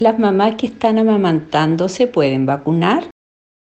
Auditores de Radio Bío Bío hicieron llegar sus consultas.